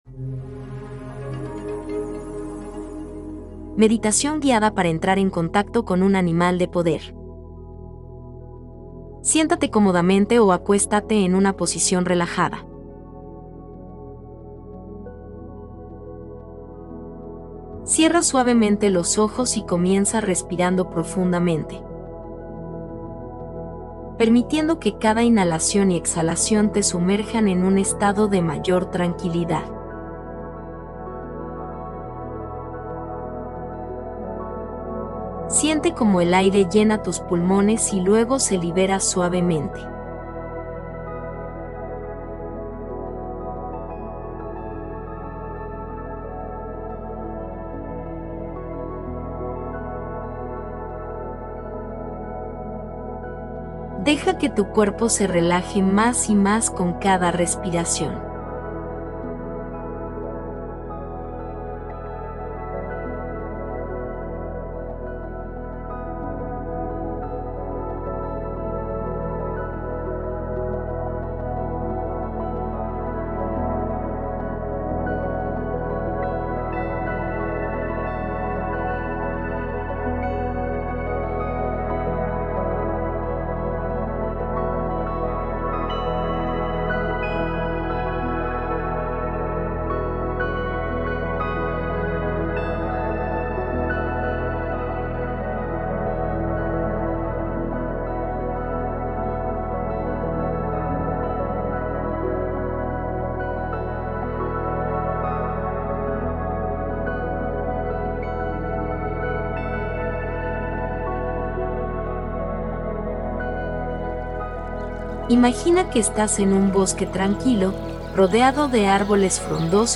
MEDITACIÓN GUIADA